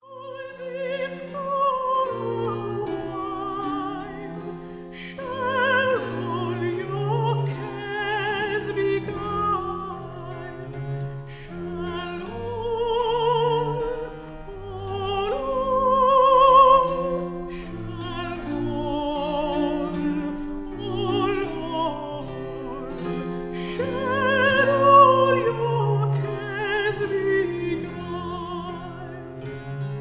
soprano
harpischord
viola da gamba